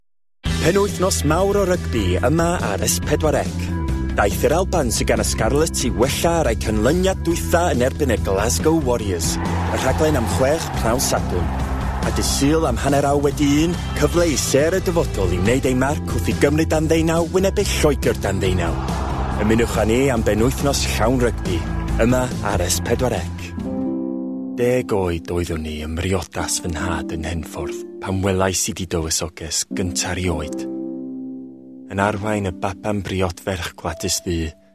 Welsh, Male, Home Studio, 20s-30s